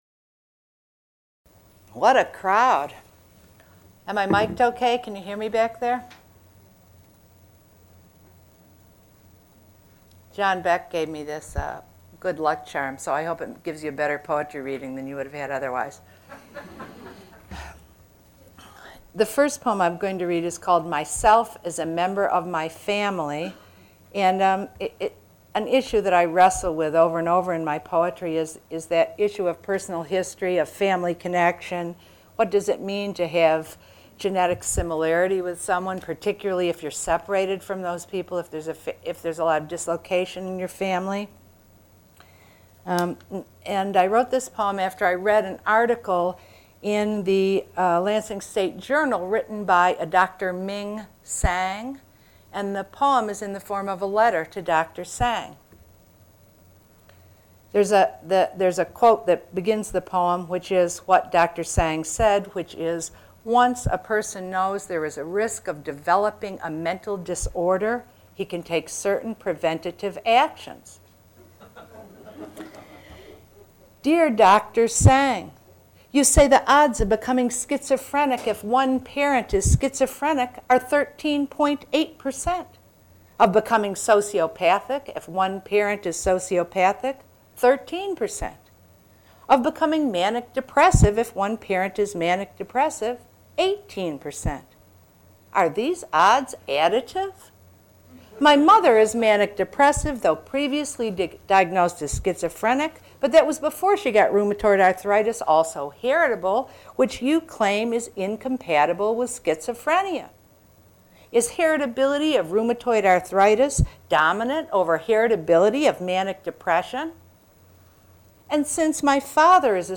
Held at the MSU Main Library.